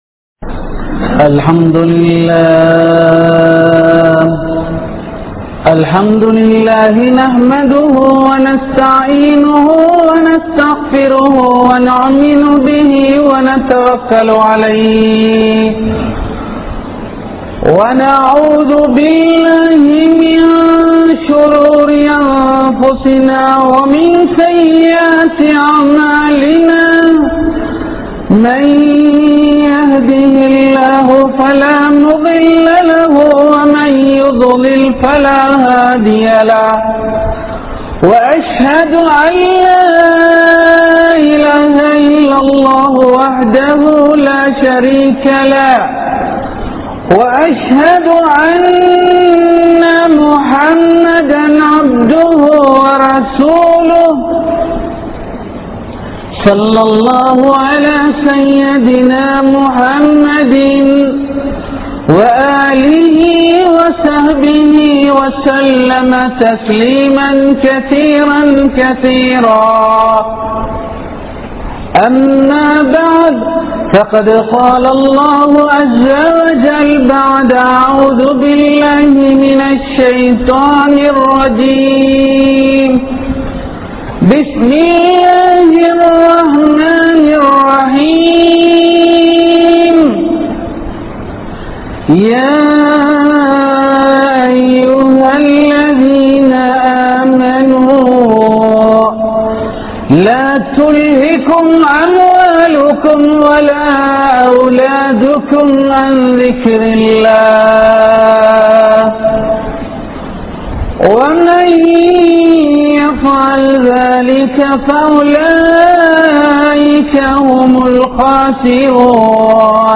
Pillaihalai Marantha Petroarhal (பிள்ளைகளை மறந்த பெற்றோர்கள்) | Audio Bayans | All Ceylon Muslim Youth Community | Addalaichenai
Grand Jumua Masjitth